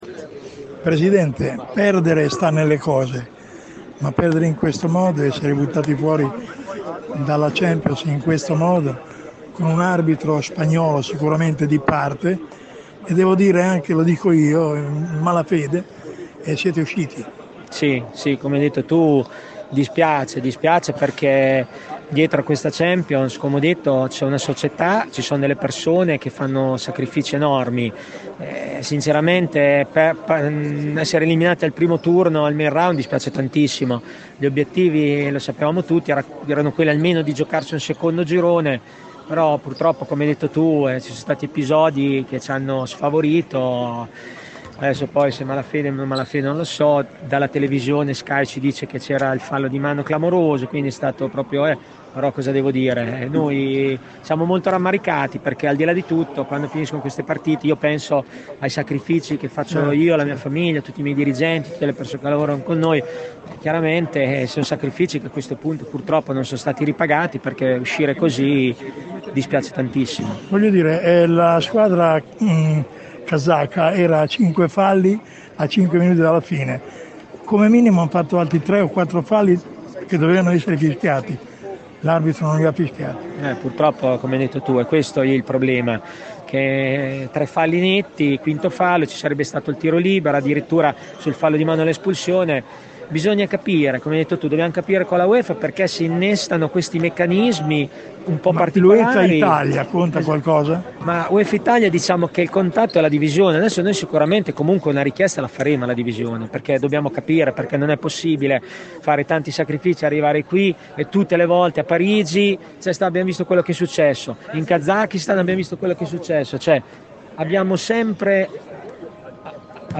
Interviste-post-kairat-champions.mp3